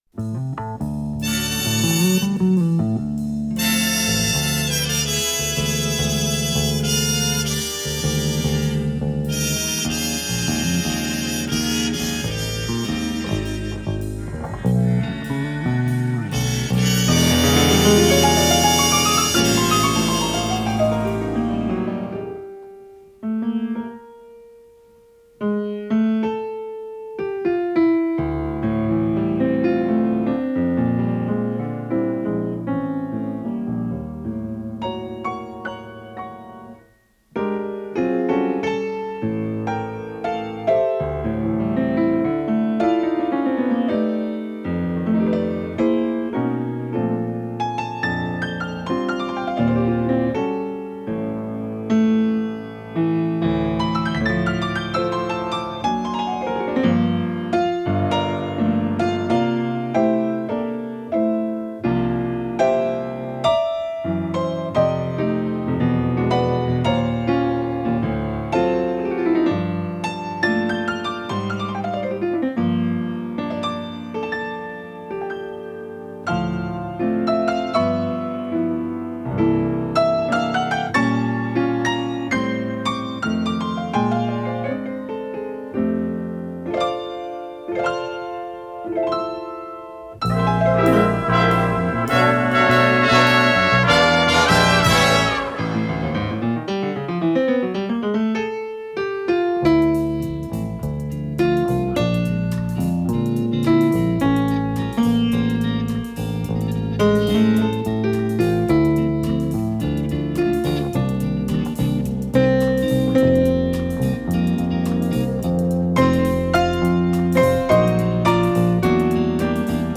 с профессиональной магнитной ленты
фортепиано